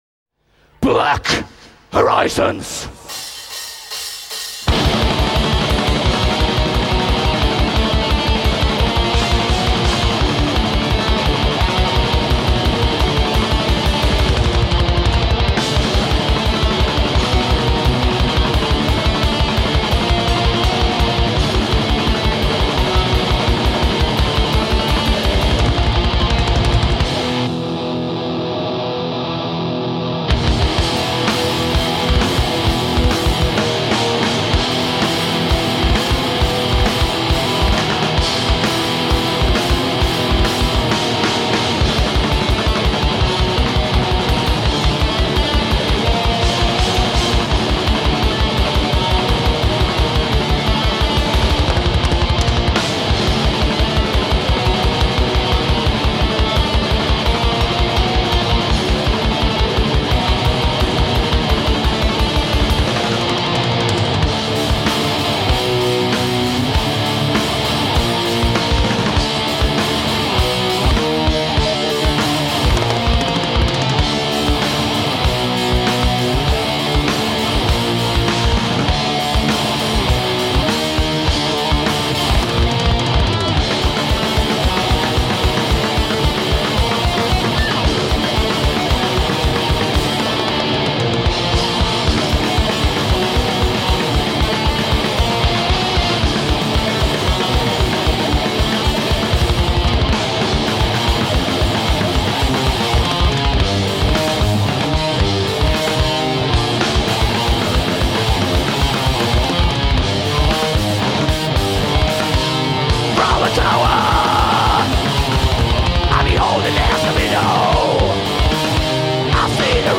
Live
Metal